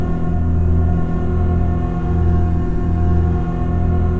sci-fi_forcefield_hum_loop_03.wav